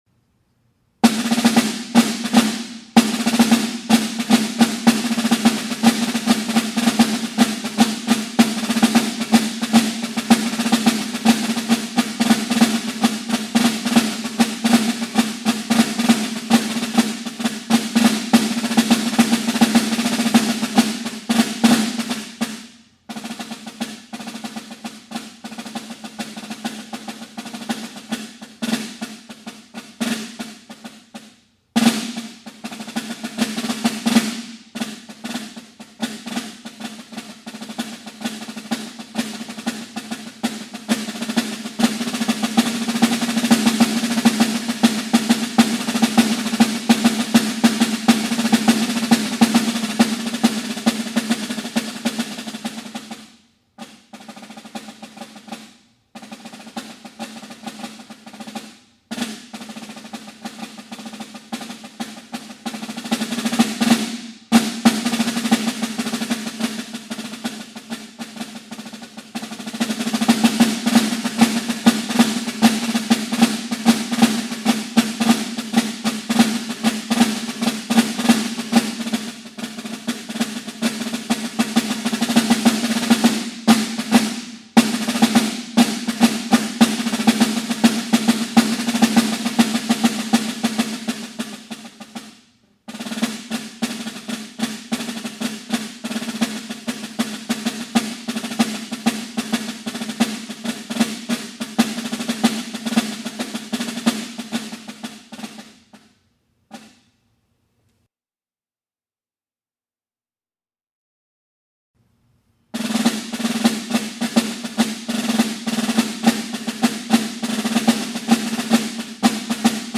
Voicing: Snare Drum